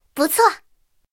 I号获得资源语音.OGG